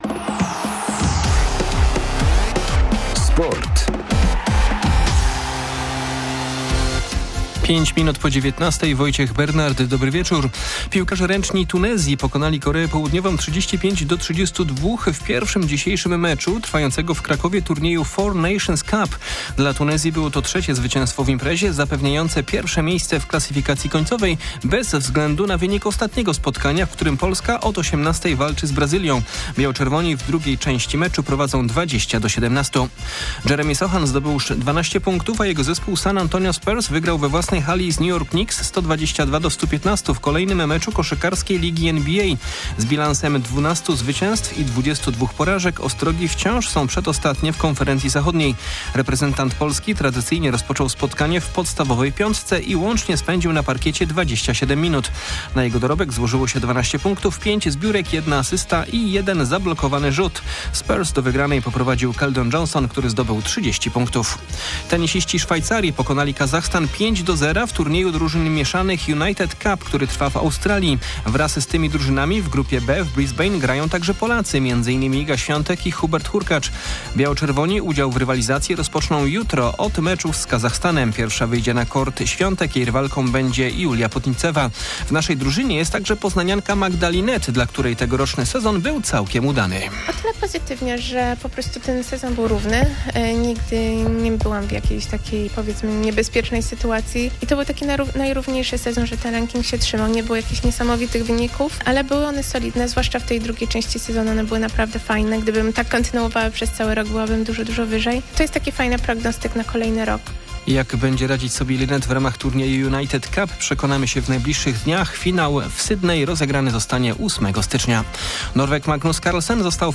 30.12.2022 SERWIS SPORTOWY GODZ. 19:05